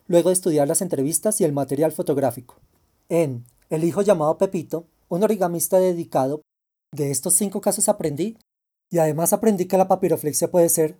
Each one is a paragraph of speech I’m reading.
I recorded them all on a smartphone application, not Audacity, using the phone’s built-in microphone.
I made and attached a WAV file by stitching together four fragments of the ones I had recorded.
The first clip has just the slightest processing distortion.